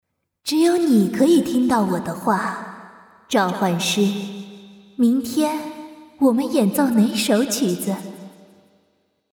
国语少年素人 、女动漫动画游戏影视 、看稿报价女游11 国语 女声 游戏 王者荣耀角色模仿-7蔡文姬 (2) 素人